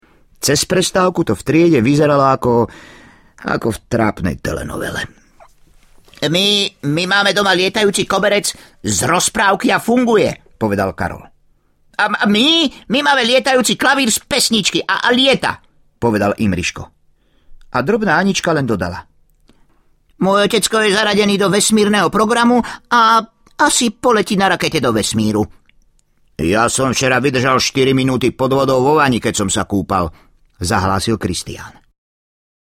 Láskavé rozprávky audiokniha
Ukázka z knihy